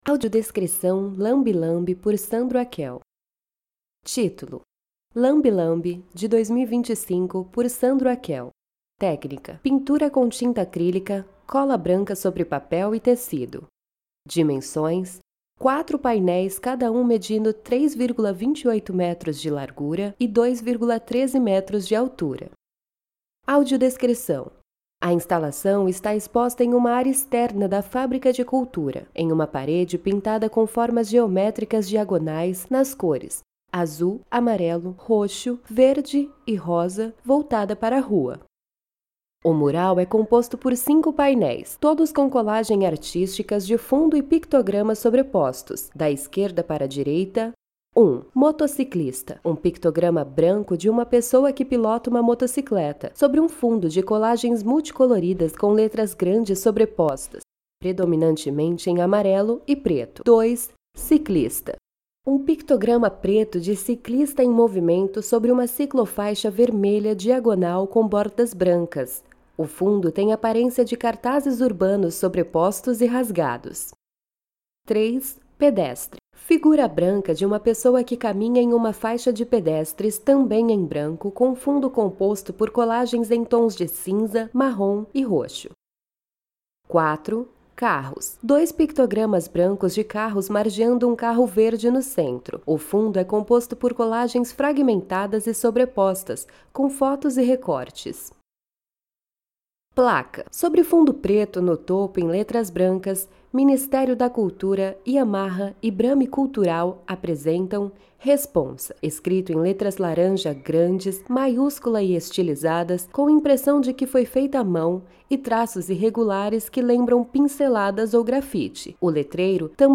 Audiodescrição da Obra